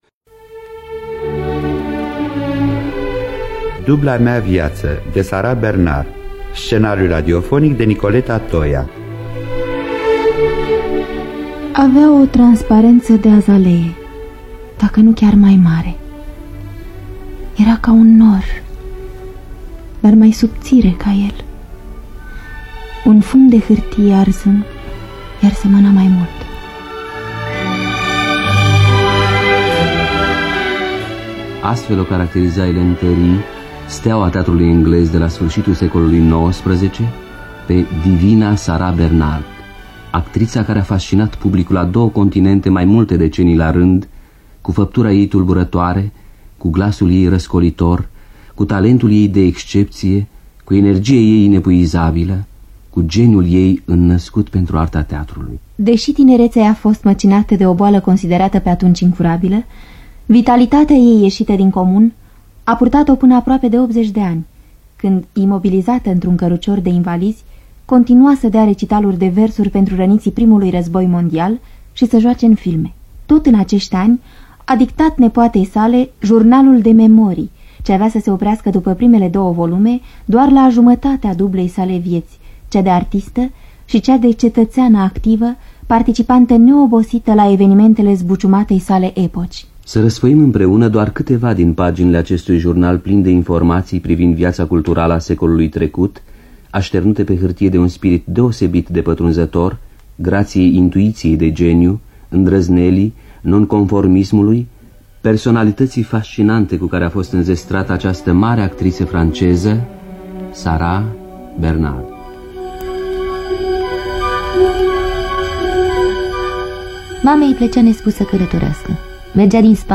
Scenariu radiofonic
Rolul titular: Gina Patrichi.